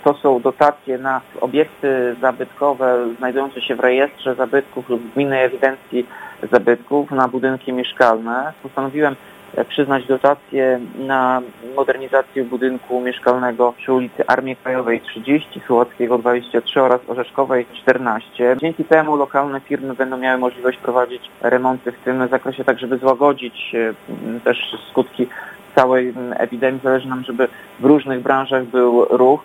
– Dotacje przyznawane są w ramach programu realizowanego przez samorząd już od lat – mówi Tomasz Andrukiewicz, prezydent Ełku.